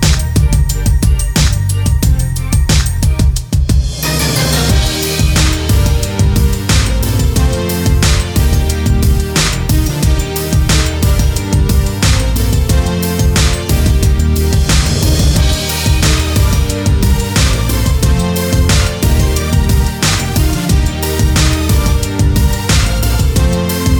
no Backing Vocals R'n'B / Hip Hop 4:00 Buy £1.50